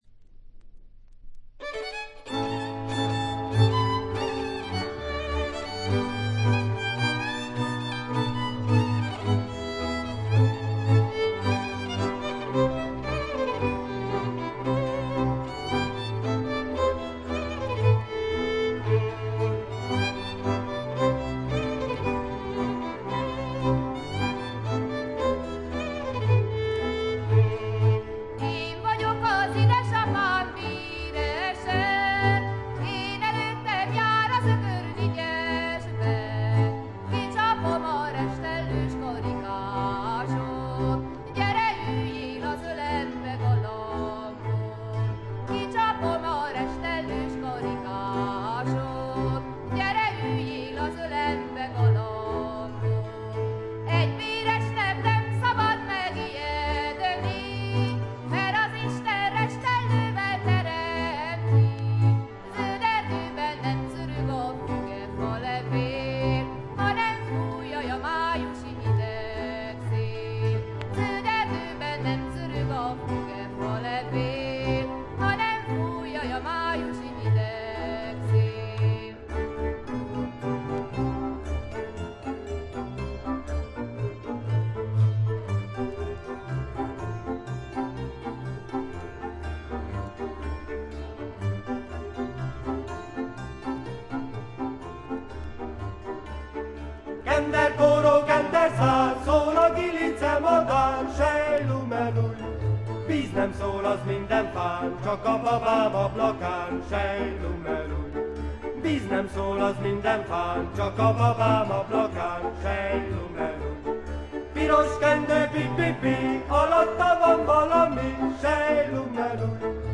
見た目よりちょっと劣り、細かなチリプチや散発的なプツ音は聴かれます。
ハンガリーの男女混成7−8人のトラッド・グループ。
エキゾチックな演奏に力強い女性ヴォーカルが響き合って得も言われぬ世界を描き出しています。
試聴曲は現品からの取り込み音源です。
Voice, Soloist